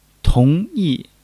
tong2-yi4.mp3